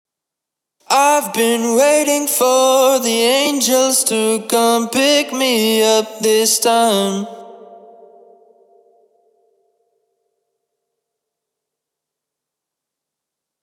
Vocal With Template Applied